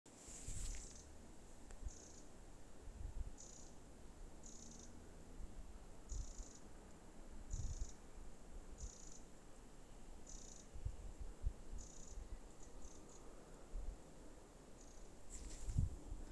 Paceplītis, Troglodytes troglodytes
StatussDzirdēta balss, saucieni